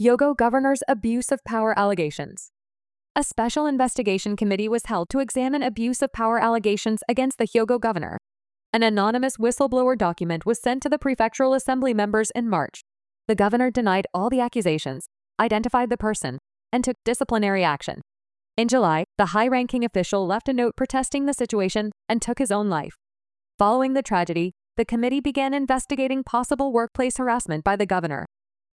Step1:全体リスニング
【ナチュラルスピード】